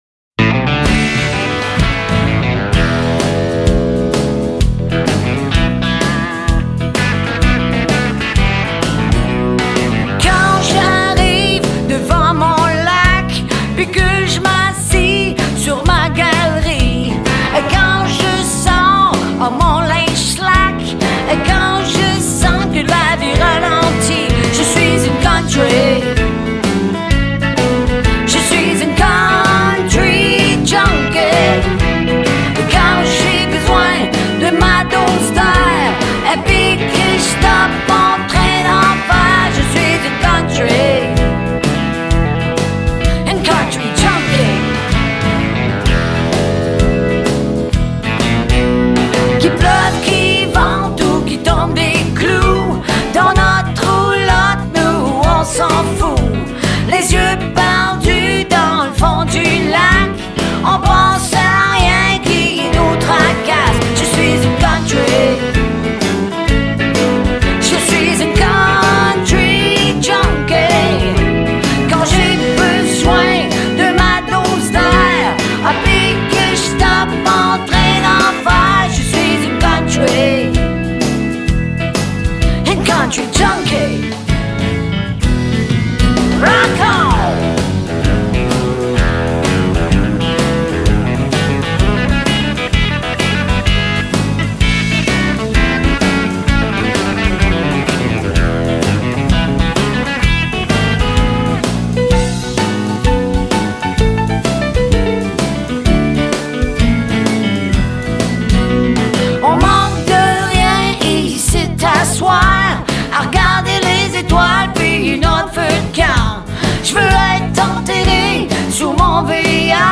amoureuse de la musique folk et country.
une reprise efficace des années’70